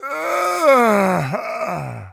die1.ogg